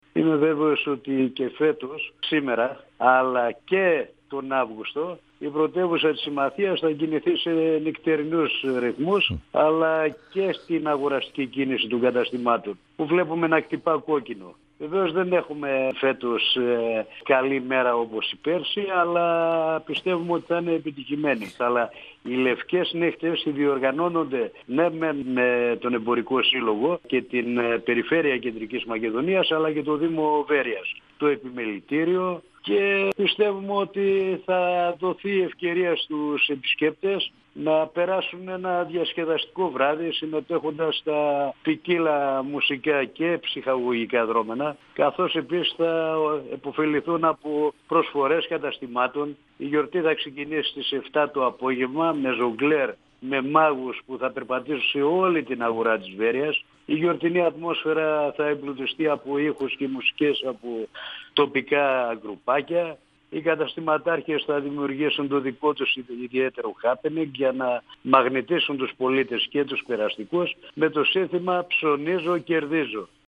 Ο  αντιπεριφερειάρχης Ημαθίας, Κώστας Καλαϊτζίδης, στον 102FM του Ρ.Σ.Μ. της ΕΡΤ3
Συνέντευξη